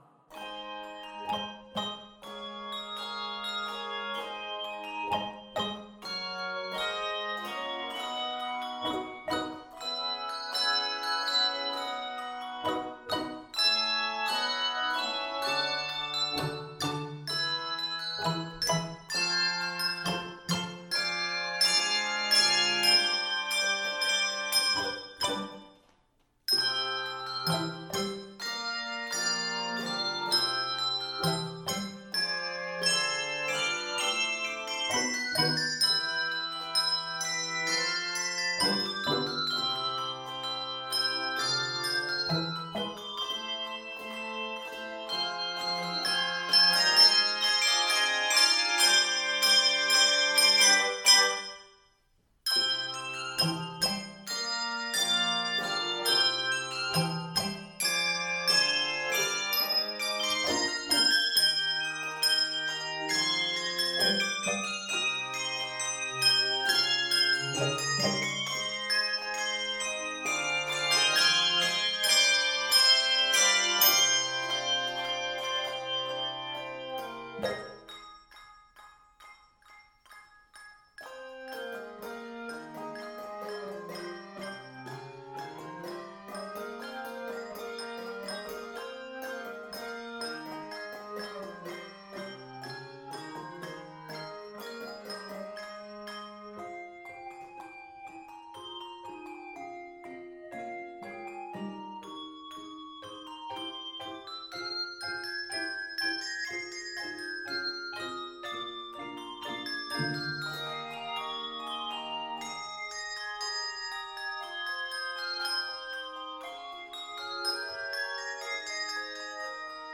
set in a lilting 9/8 time
N/A Octaves: 3-6 Level